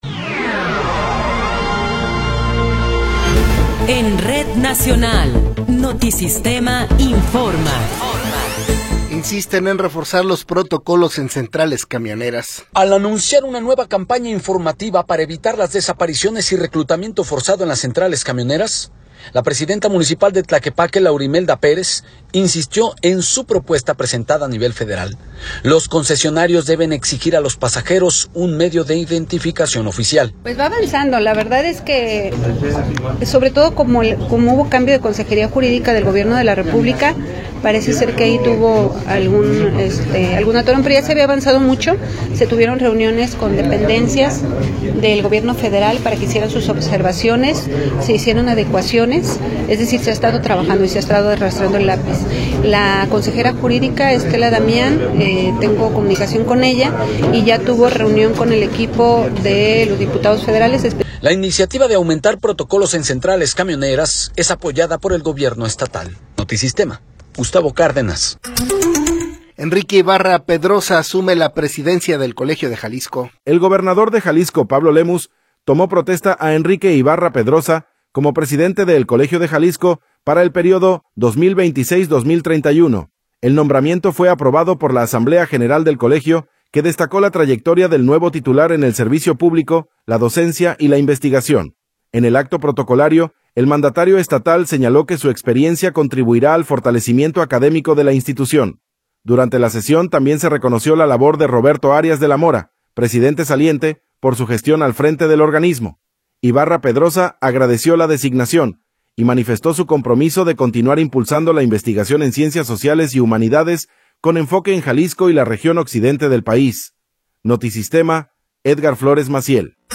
Noticiero 17 hrs. – 27 de Febrero de 2026
Resumen informativo Notisistema, la mejor y más completa información cada hora en la hora.